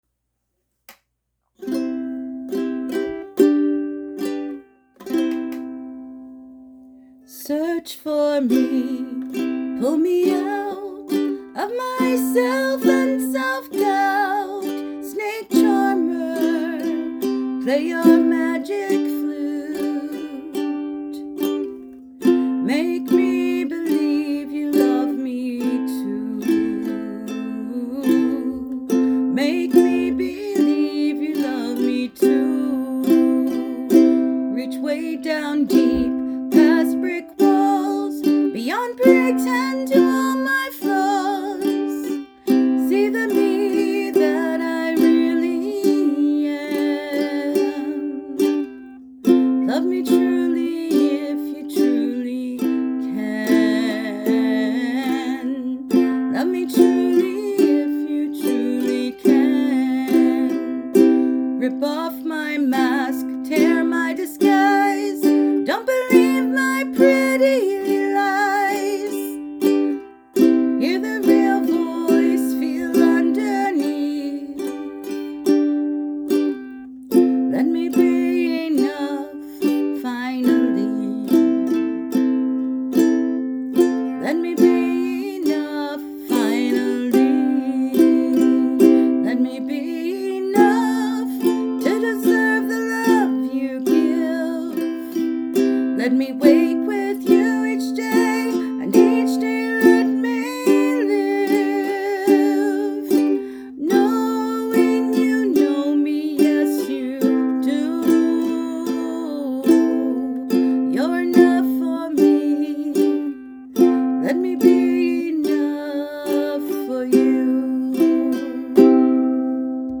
I loved listening to your sweet singing while reading your wonderful poem.
Your melody, voice and strumming blend seamlessly to create a wonderful, emotive vocal and poetic experience.
Aww I think this is your best melody yet (your ukelele strumming puts me in mind of Tiny Tim ha!).
Strumming is the most difficult part for me.